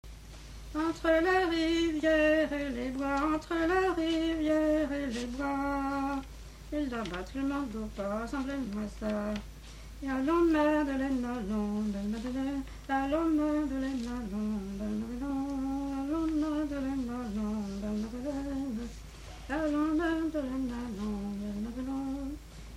danse : ronde : grand'danse
Genre strophique
Pièce musicale inédite